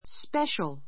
spéʃəl